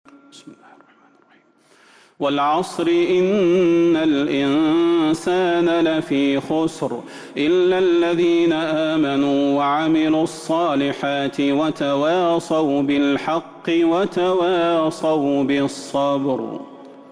سورة العصر Surat Al-'Asr من تراويح المسجد النبوي 1442هـ > مصحف تراويح الحرم النبوي عام 1442هـ > المصحف - تلاوات الحرمين